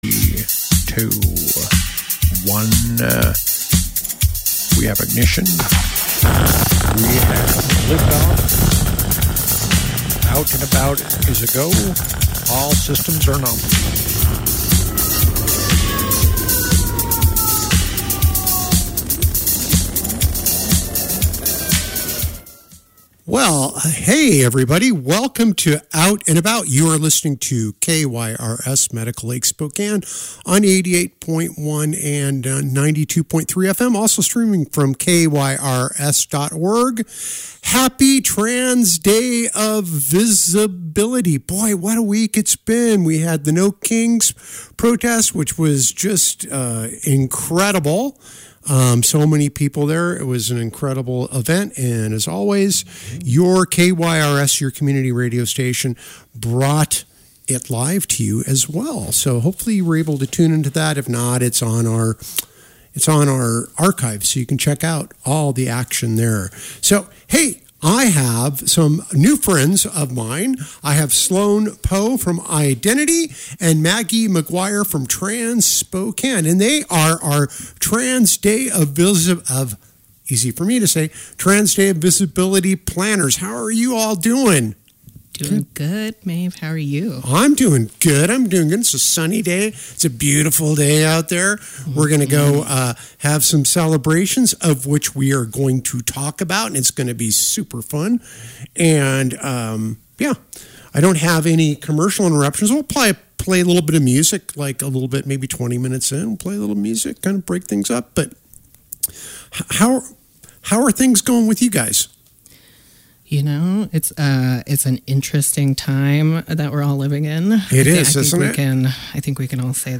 Unfortunately, the computer that has the underwriting announcements on it was down for the day.